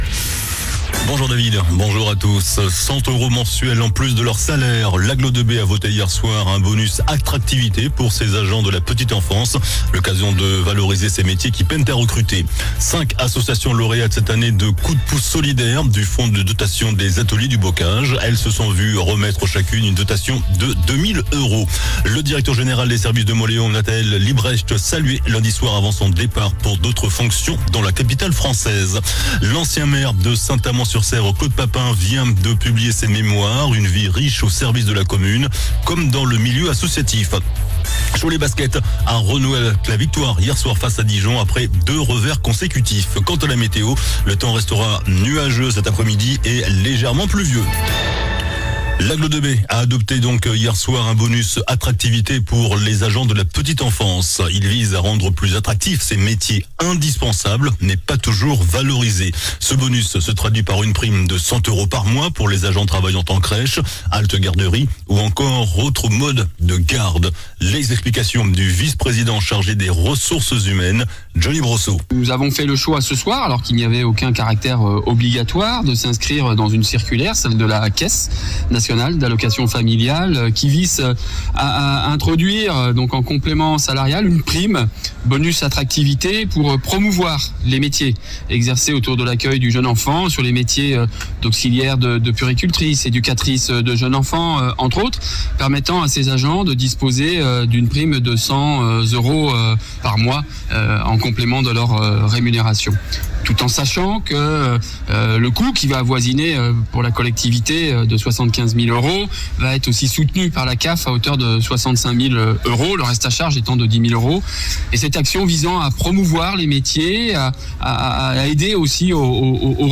JOURNAL DU MERCREDI 18 DECEMBRE ( MIDI )